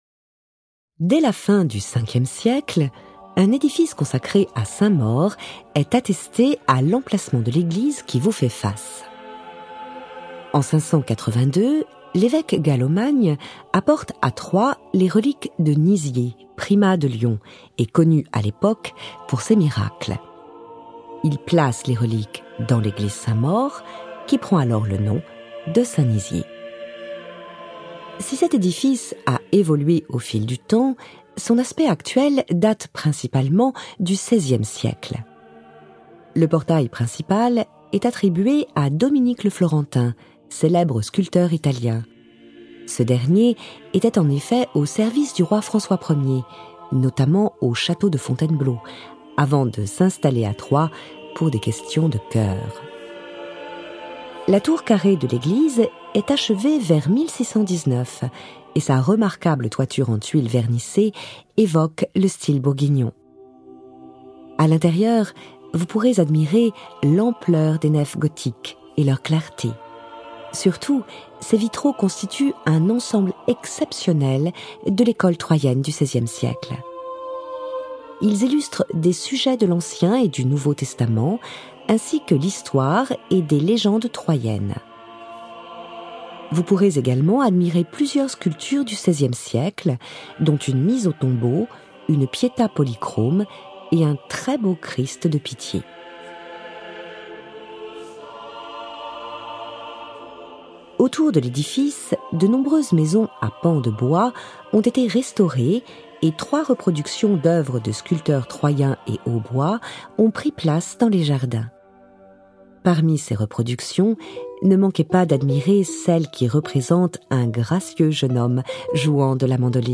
Cette balade audio-guidée vous permet de découvrir par vous-même tous les lieux importants de la ville de Troyes, tout en bénéficiant des explications de votre guide touristique numérique.